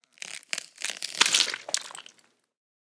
horror
Gore Rip Flesh 3